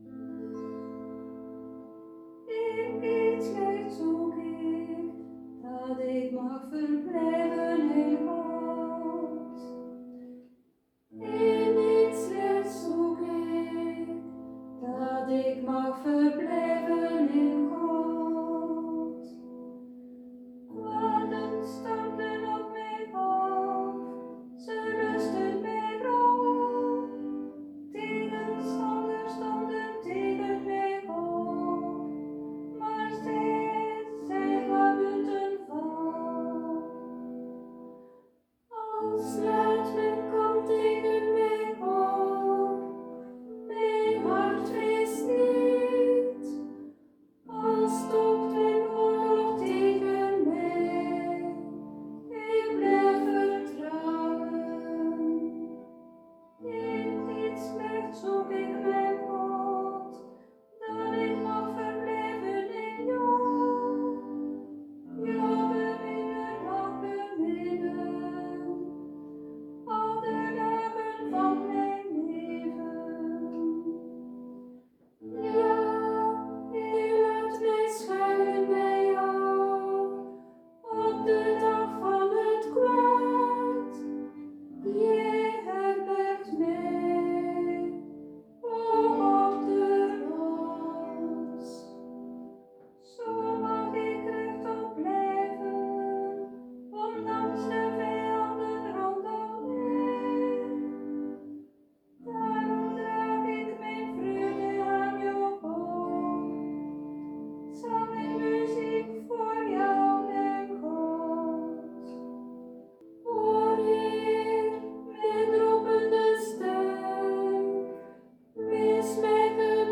met citerbegeleiding